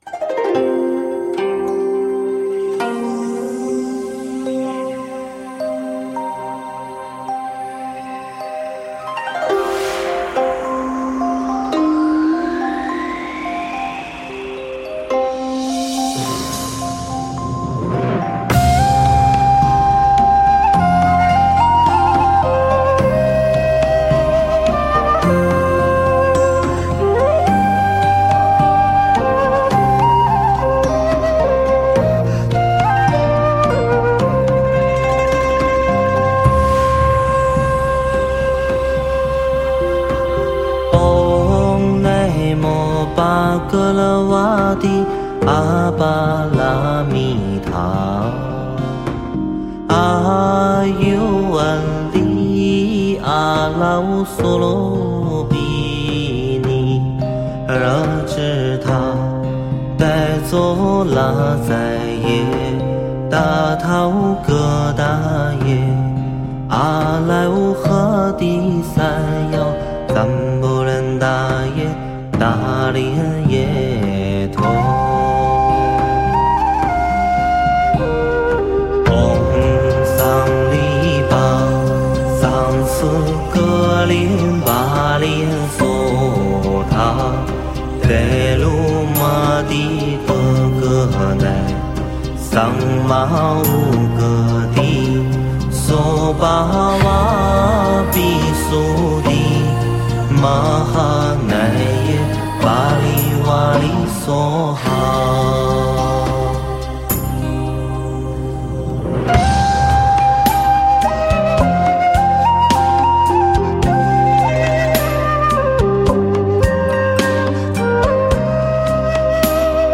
此陀罗尼是《早晚课诵集》中十小咒之一。